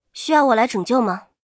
尘白禁区_安卡希雅语音_登场1.mp3